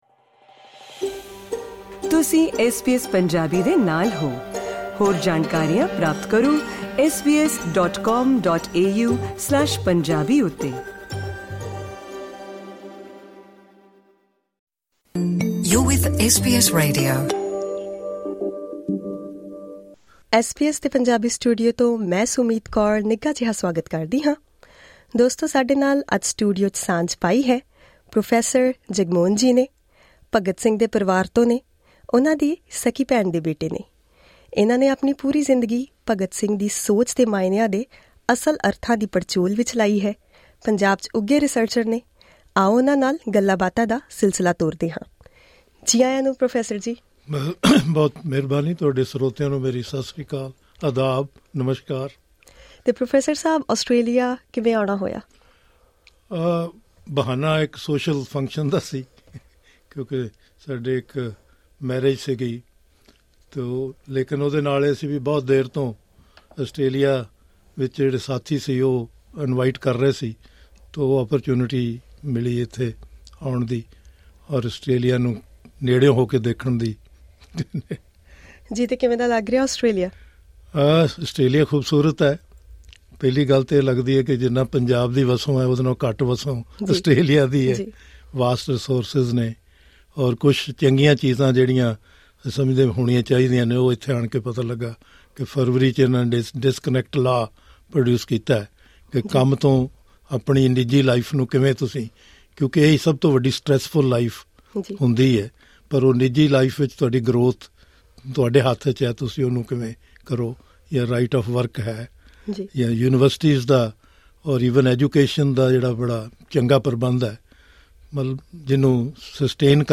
at SBS Studios, Melbourne
interview